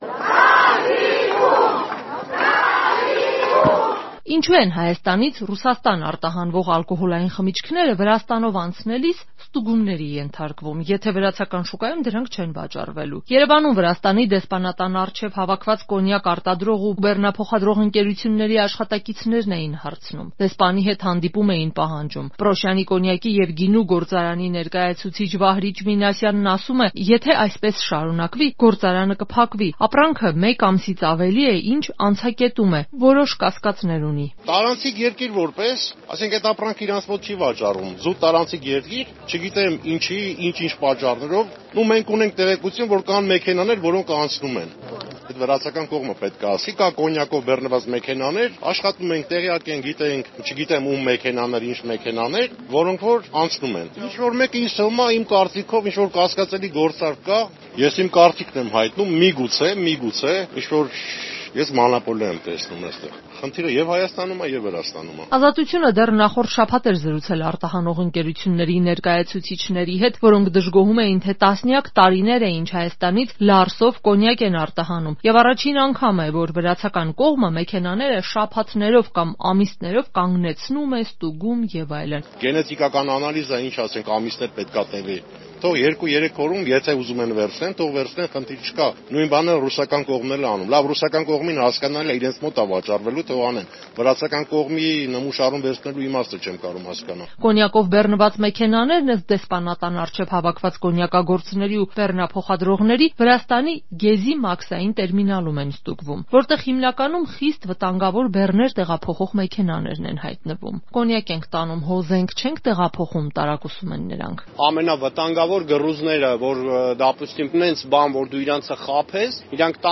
Կոնյակագործների և բեռնափոխադրողների բողոքի ակցիա՝ Երևանում Վրաստանի դեսպանատան դիմաց
Ռեպորտաժներ